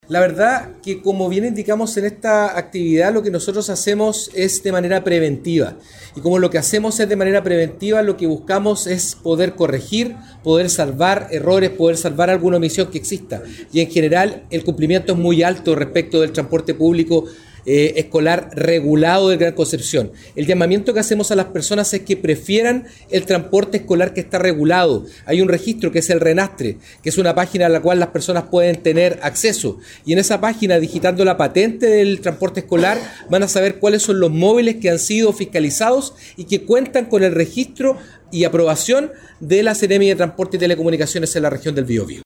Cuna-2-Seremi-de-transporte-Patricio-Fierro-transporte-escolar.mp3